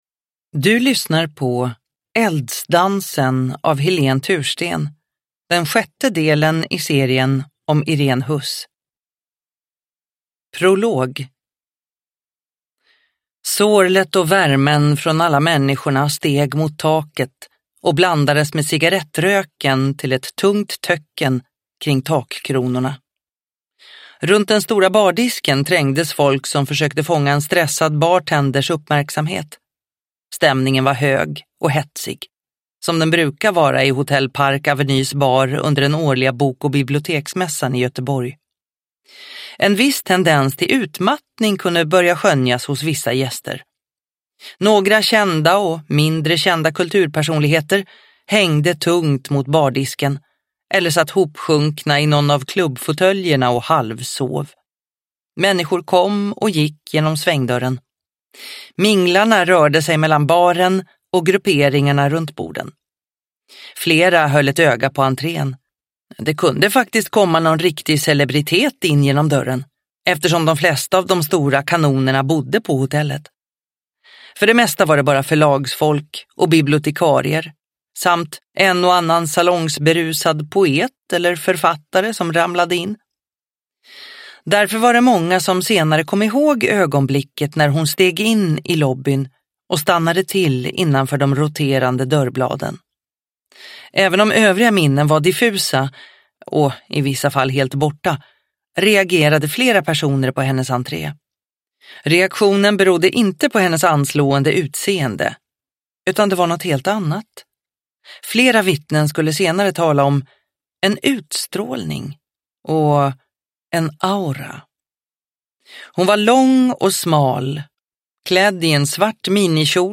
Eldsdansen – Ljudbok – Laddas ner